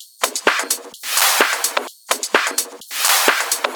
VEH1 Fx Loops 128 BPM
VEH1 FX Loop - 21.wav